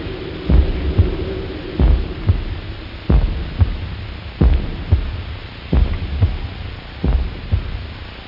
Heartbeat Sound Effect
Download a high-quality heartbeat sound effect.
heartbeat.mp3